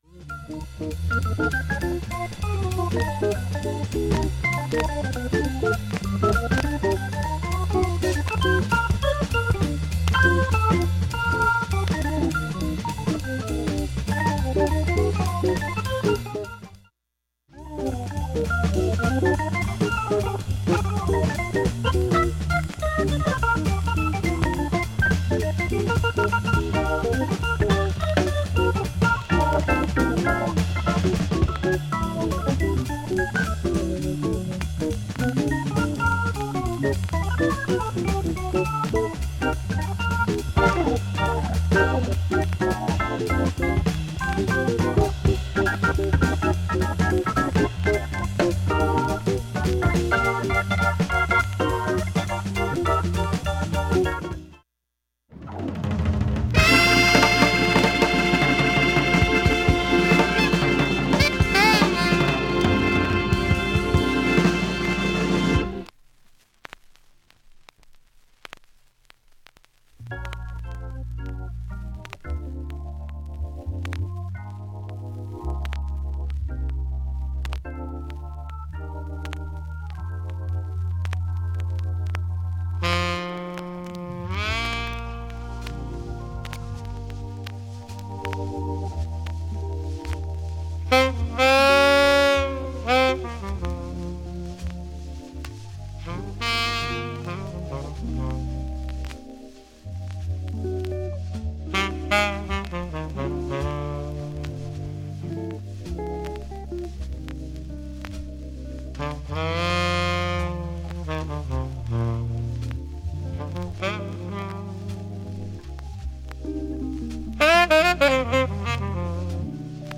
３回までのかすかなプツが４箇所 単発のかすかなプツが１５箇所